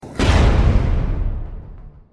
SFX惊奇悬疑震惊悚配乐音效下载
SFX音效